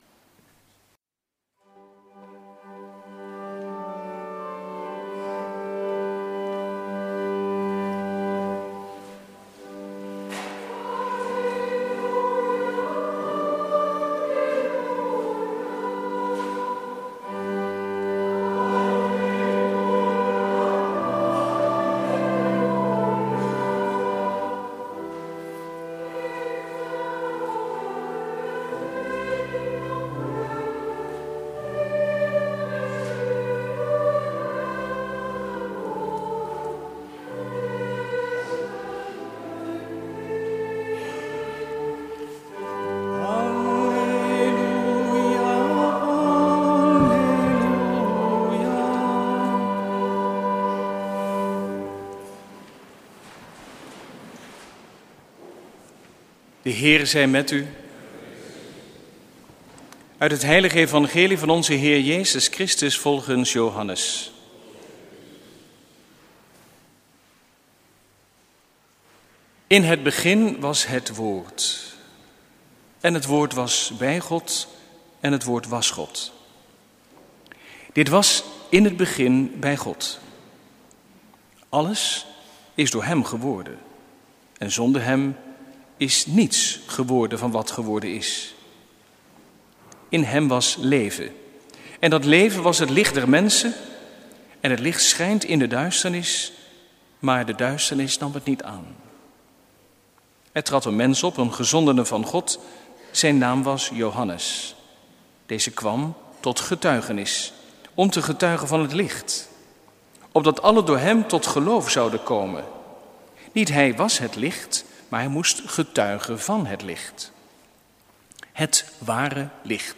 Eucharistieviering beluisteren vanuit de Goede Herder te Wassenaar (MP3)